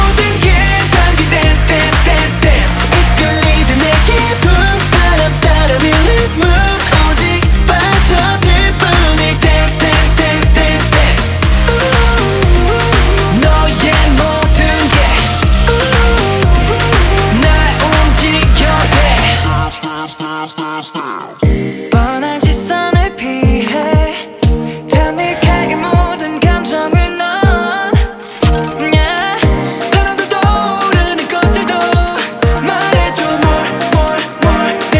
Жанр: Танцевальные / Поп / K-pop